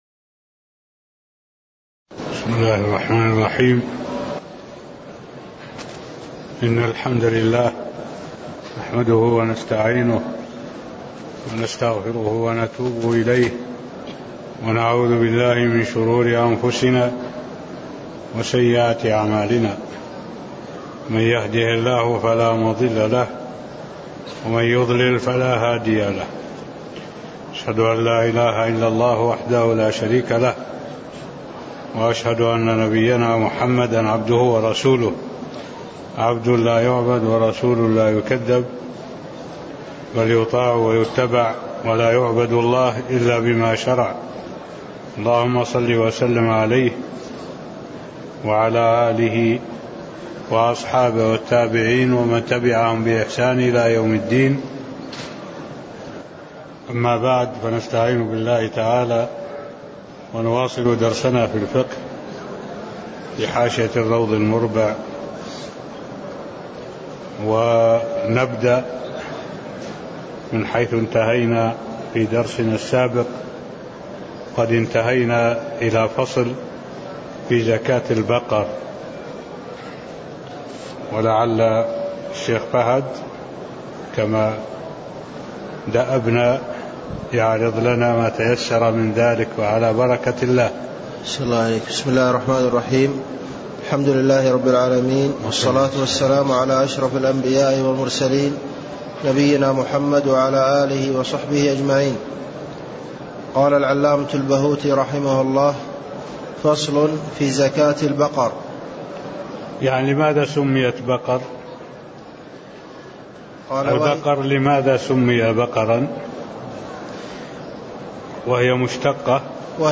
تاريخ النشر ٢٨ ربيع الأول ١٤٢٩ هـ المكان: المسجد النبوي الشيخ: معالي الشيخ الدكتور صالح بن عبد الله العبود معالي الشيخ الدكتور صالح بن عبد الله العبود زكاة البقر (008)